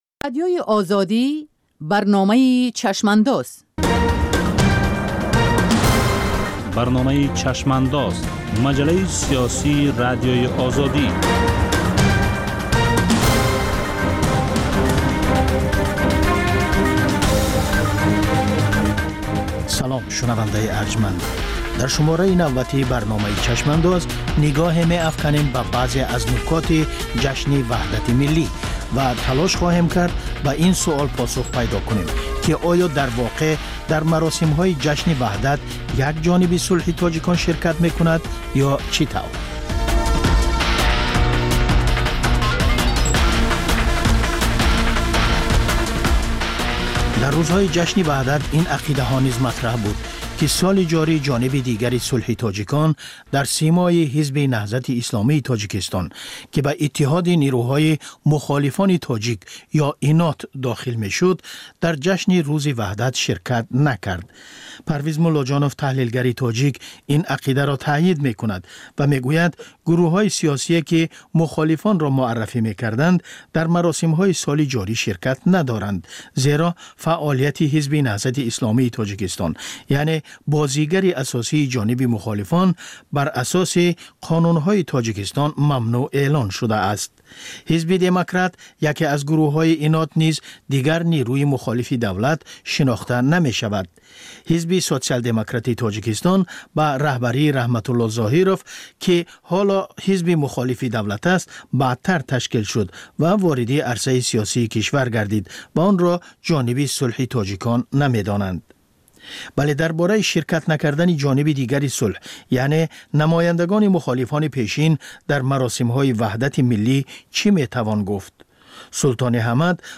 Ҷусторе дар рӯйдодҳои сиёсии ҷаҳон, минтақа ва Тоҷикистон дар як ҳафтаи гузашта. Мусоҳиба бо таҳлилгарони умури сиёсӣ.